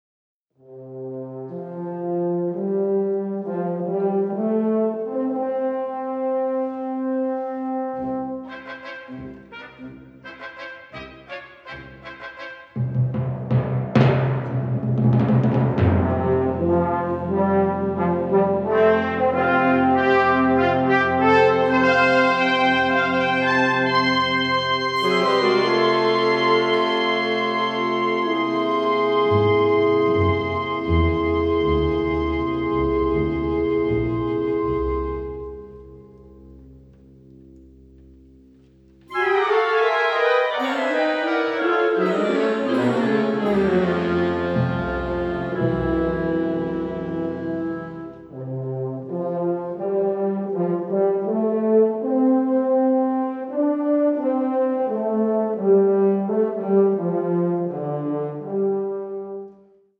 Catégorie Harmonie/Fanfare/Brass-band
Sous-catégorie Rhapsodies
Instrumentation Ha (orchestre d'harmonie)